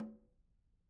Snare2-HitNS_v1_rr1_Sum.wav